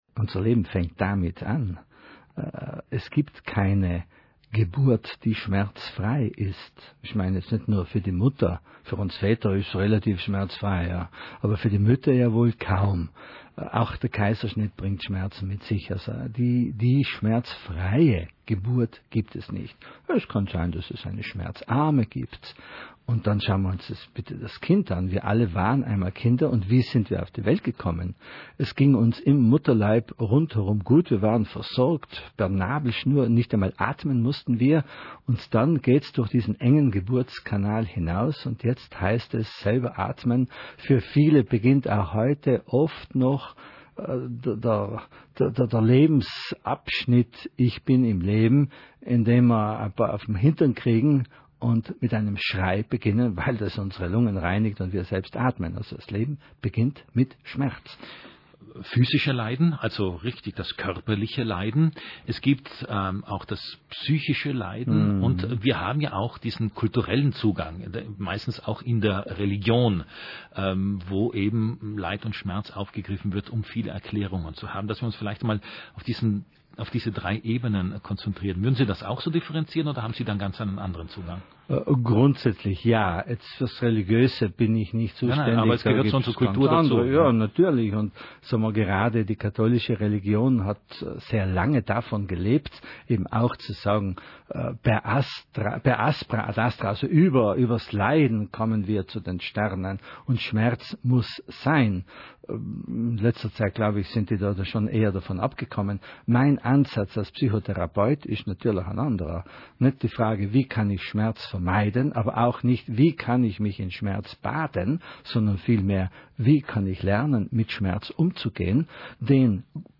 RAI-Beraterradio-Leid-Schmerz-Live-Ausschnitte.mp3